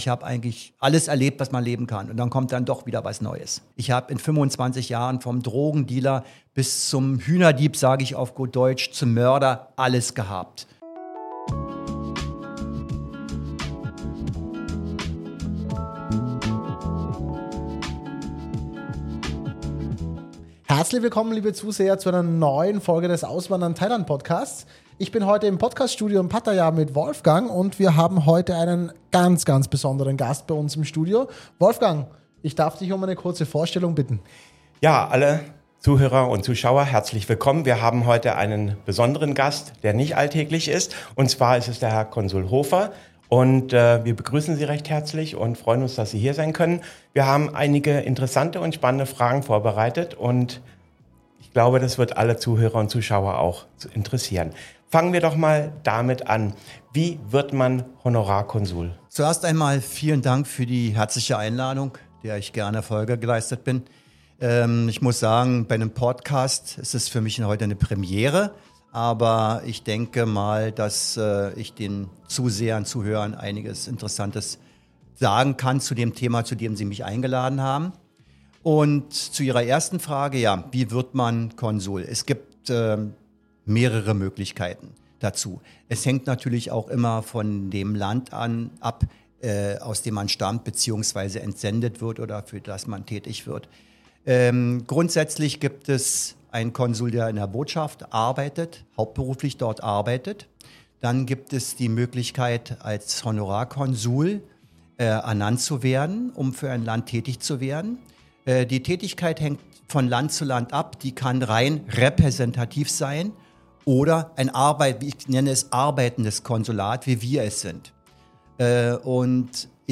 Konsularische Aufgaben und Herausforderungen in Thailand: Konsul Hofer im Interview über Leben, Verantwortung und Auswandern.
konsul-hofer-interview.mp3